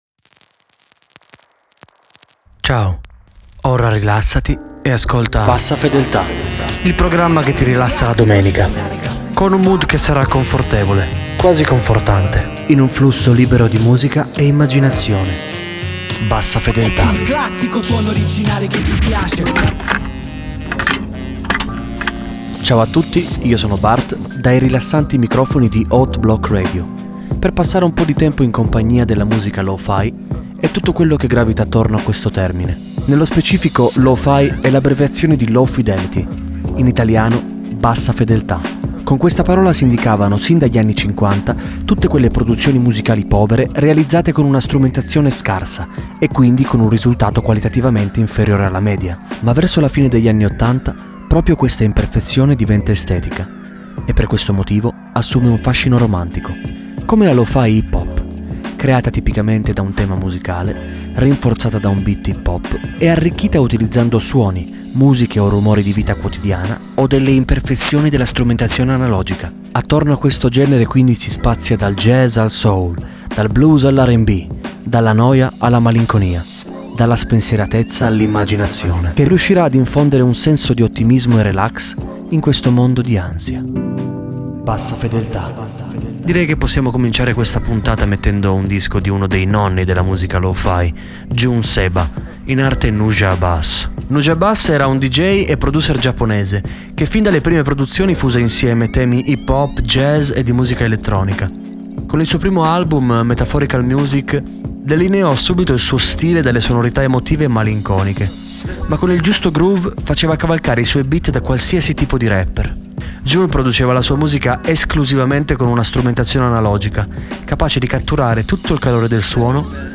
Lo Fi